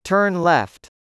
3. turn left /tɜːrn left/: rẽ trái